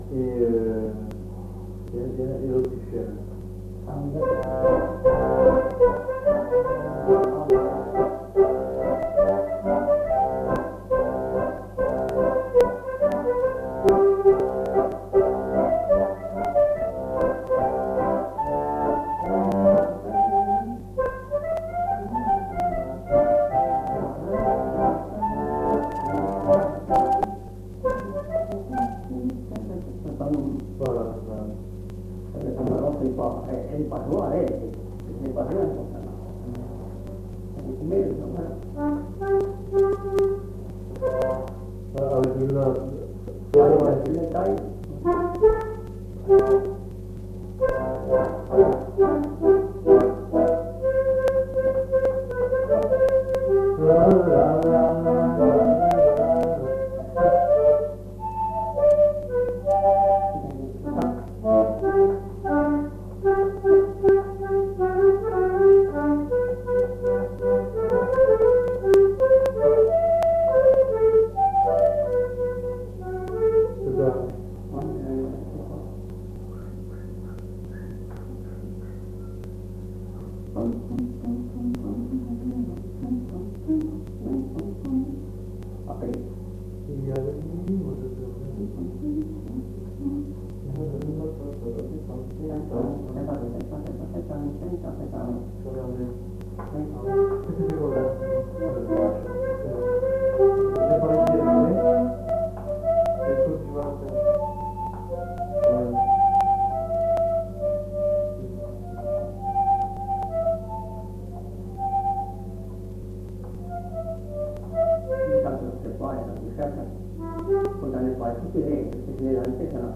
Aire culturelle : Marmandais gascon
Lieu : Escassefort
Genre : morceau instrumental
Instrument de musique : accordéon diatonique
Danse : autrichienne
Notes consultables : Morceau d'abord proposé par le collecteur puis joué une seconde fois par l'interprète. En fin de séquence une discussion avec évocation de musiciens.